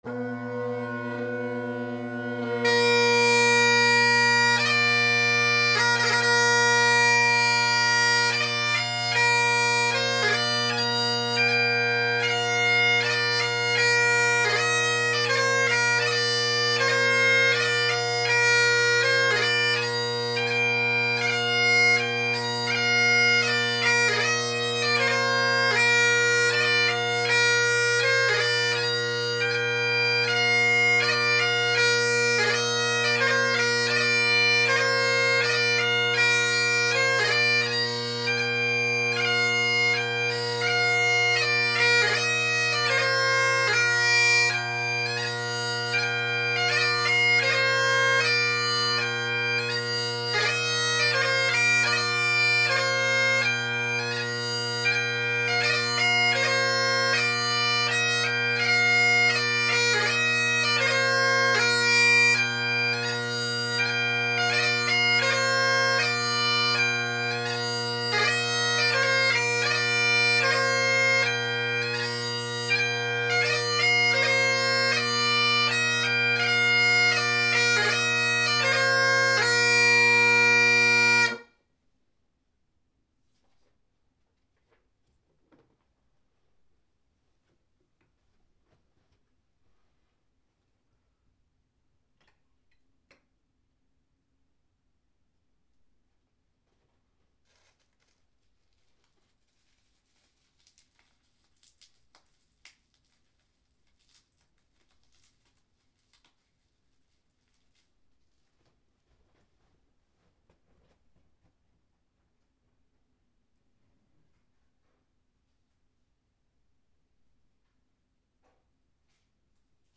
Drone Sounds of the GHB, Great Highland Bagpipe Solo
I play the same tune in each recording; a new composition of mine.
Carbon fiber tongued Canning bass drone reed – sorry about the silence at the end, audio program wouldn’t cut it out